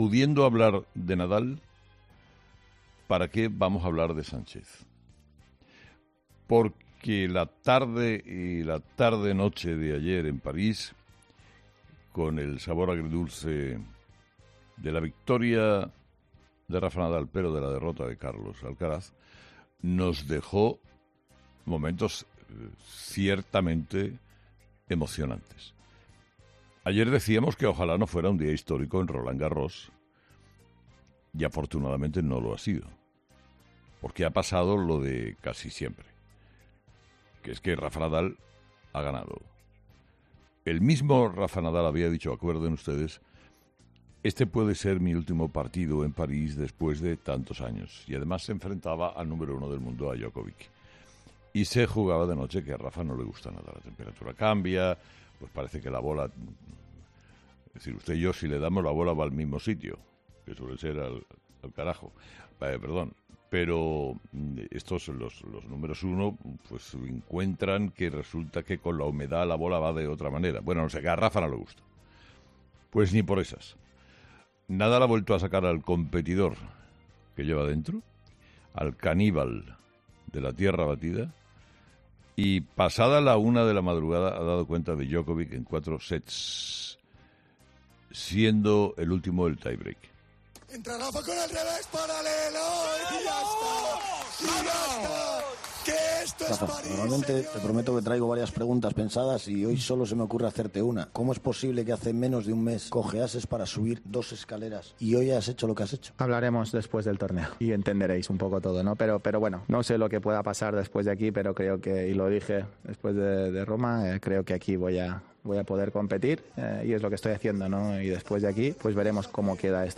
Carlos Herrera, director y presentador de 'Herrera en COPE', ha comenzado el programa de este miércoles analizando las principales claves de la jornada, que pasan, entre otros asuntos, por la victoria de Rafa Nadal en cuartos de final de Roland Garros ante Djokovic en París y por el cuarto aniversario de la moción de censura de Pedro Sánchez contra Mariano Rajoy.